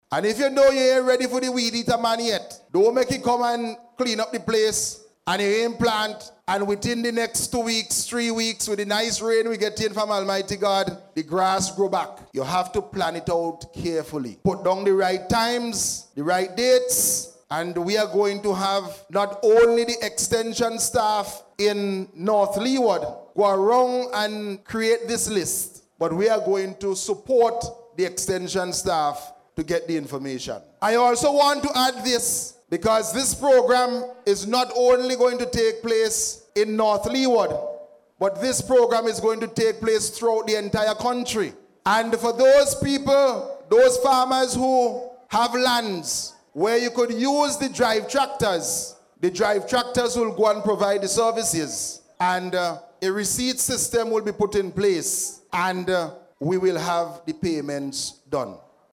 Speaking at the official launch of the programme in Troumaca on Tuesday, Minister Caesar said registration for services provided under the program will continue this week and next week.